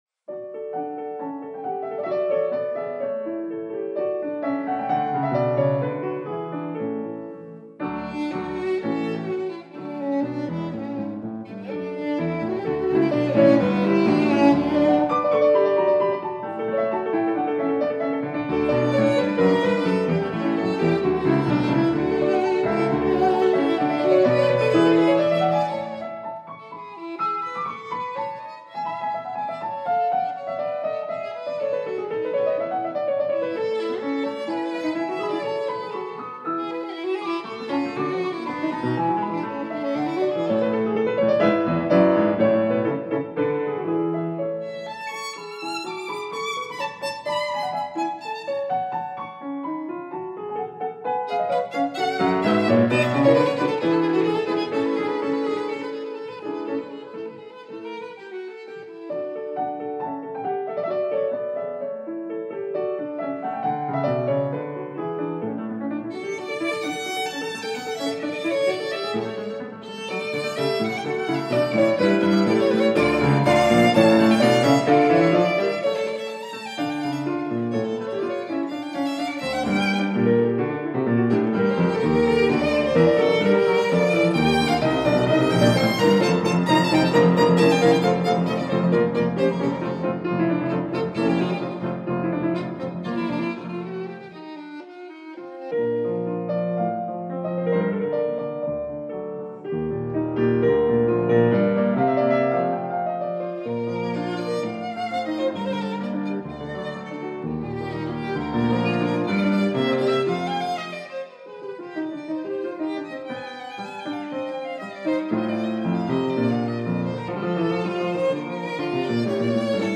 piano
violin